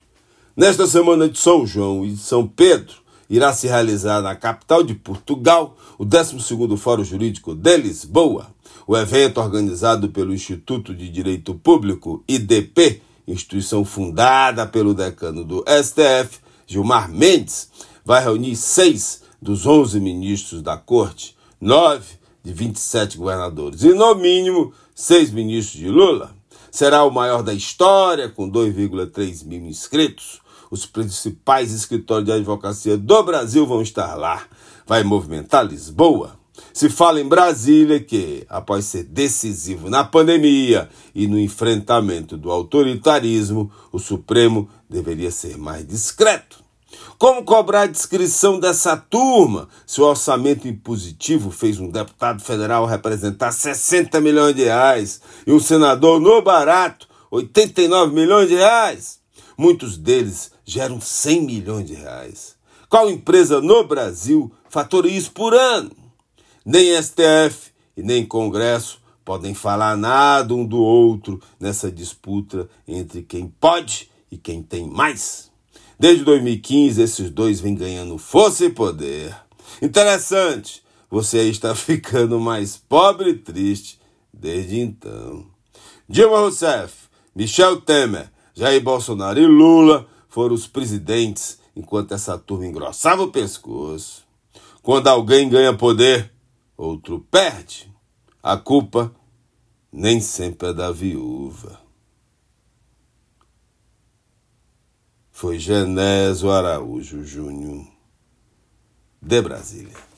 Comentário desta segunda-feira